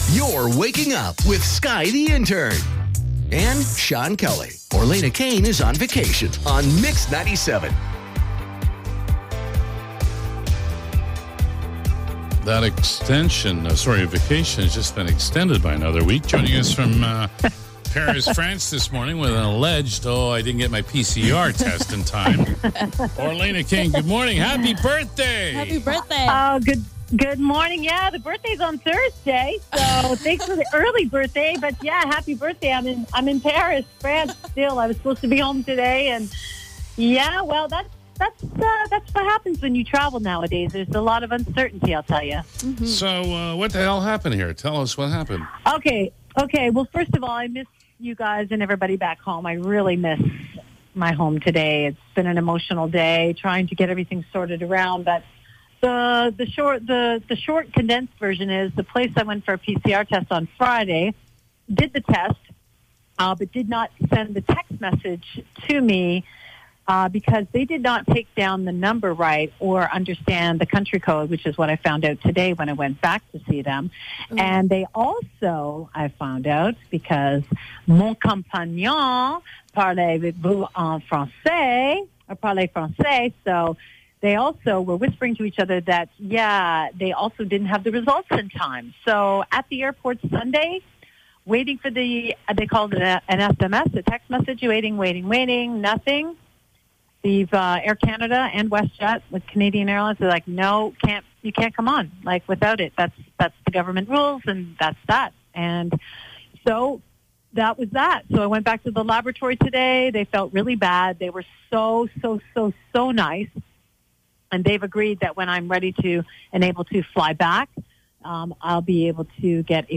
We got her on the phone this morning and were able to hear about her vacation, and the reason for her having to stay another week!